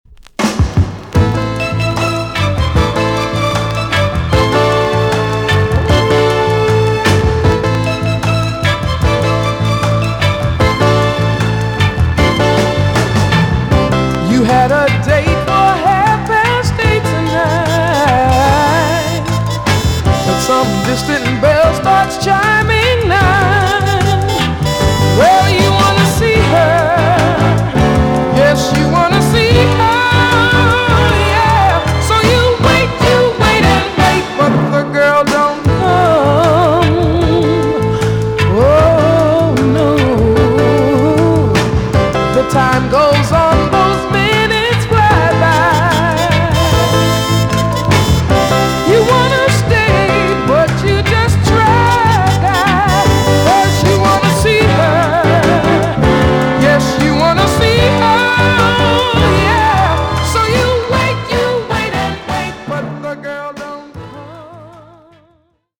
EX-~VG+ 少し軽いチリノイズがありますが良好です。